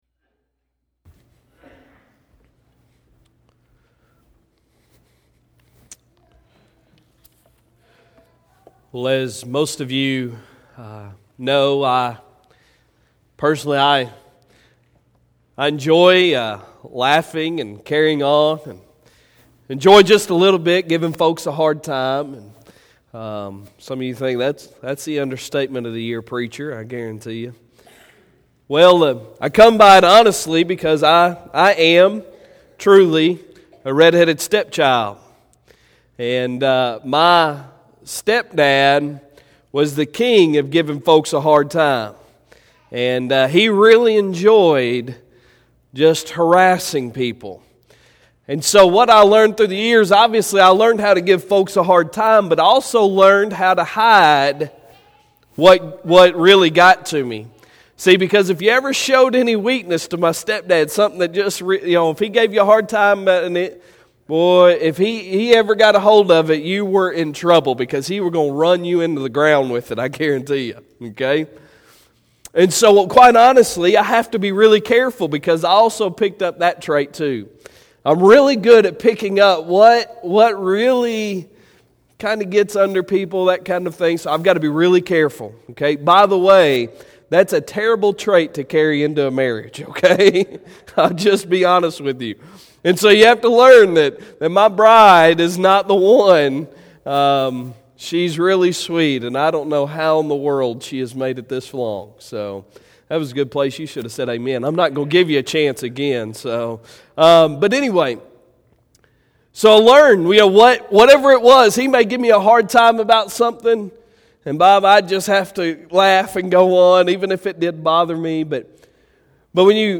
Sunday Sermon December 1, 2019